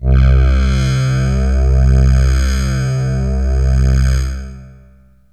AMBIENT ATMOSPHERES-3 0005.wav